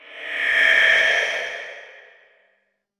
Creepy Whisper
An unsettling whispered voice barely audible with breathy texture and eerie reverb
creepy-whisper.mp3